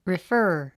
発音
rifə’ːr　リファー